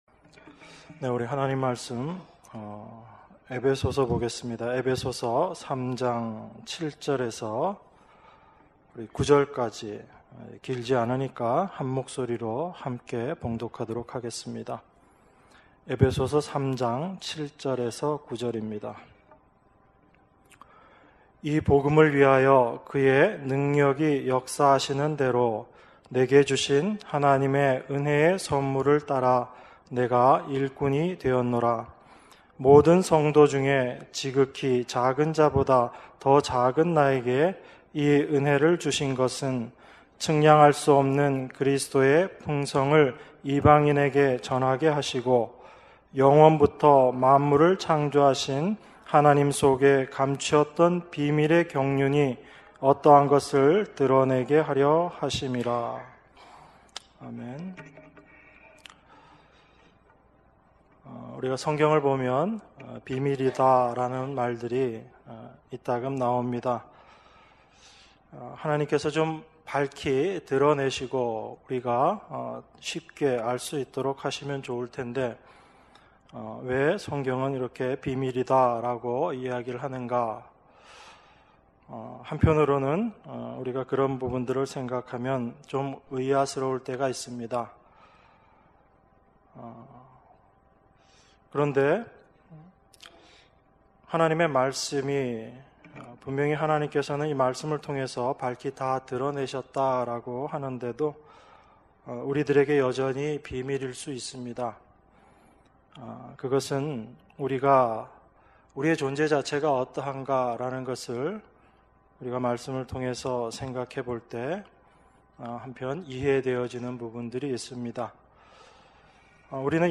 주일예배 - 에베소서 3장 7절~9절 주일2부